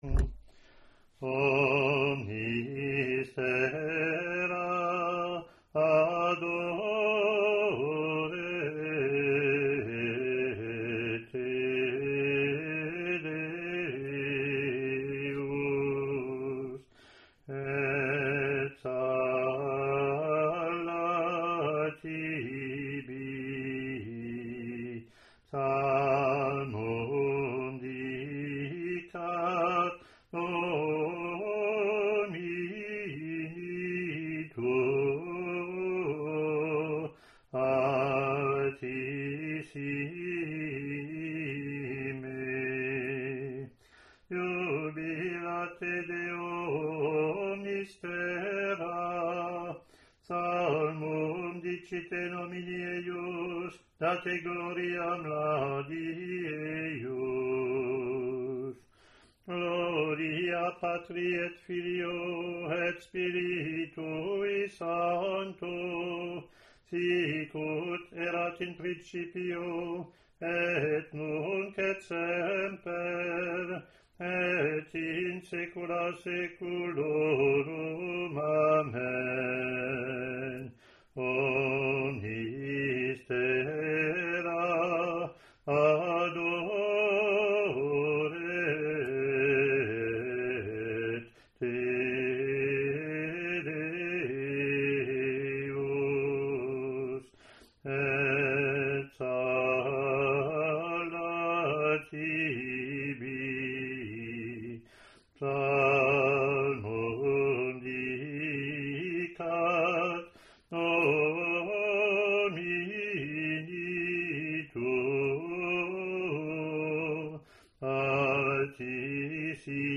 Latin antiphon+verses)
ot02-introit-gm.mp3